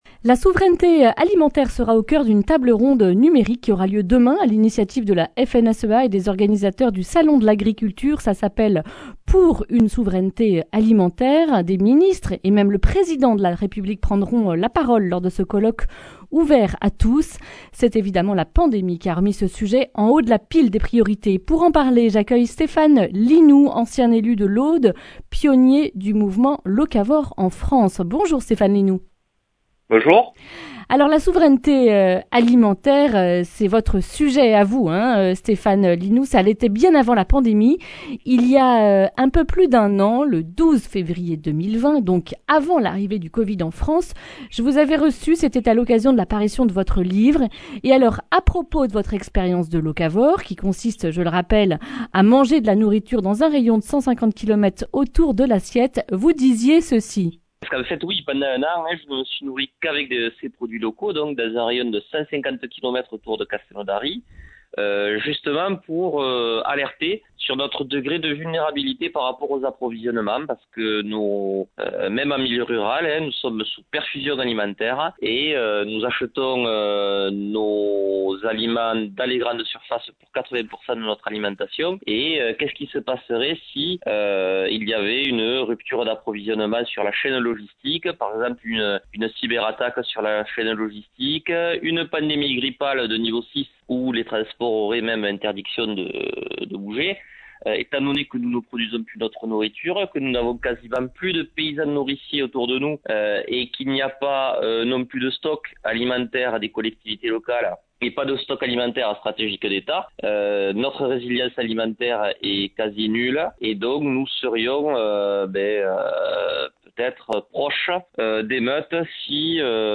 Accueil \ Emissions \ Information \ Régionale \ Le grand entretien \ Souveraineté alimentaire : où en sommes-nous ?